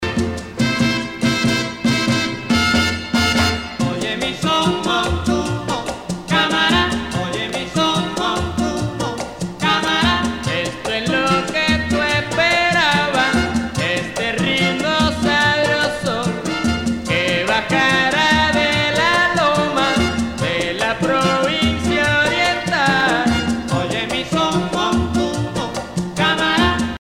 Usage d'après l'analyste gestuel : danse
Pièce musicale éditée